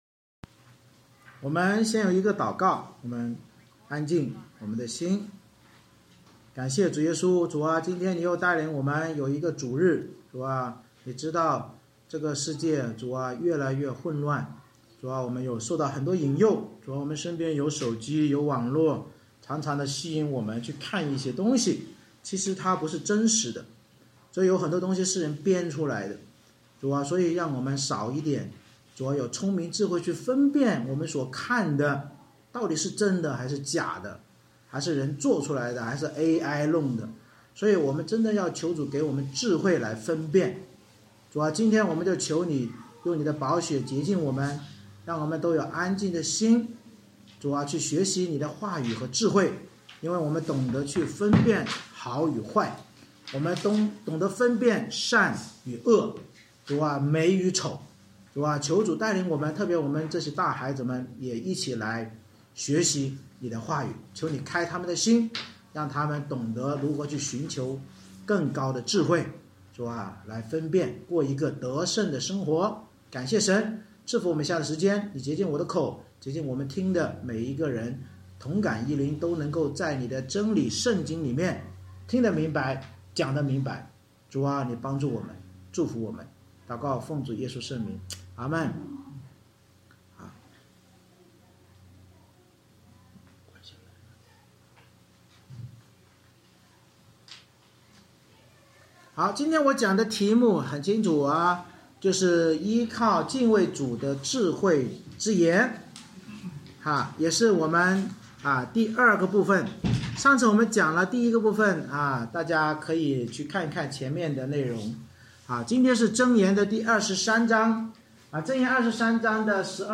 箴言23：12-35 Service Type: 主日崇拜 借着对依靠与敬畏主的智慧之言中三个“我儿”的教导，警告我们如果听从与存记父辈的圣经智慧并将心归主就能脱离贪酒好食的悲惨结局。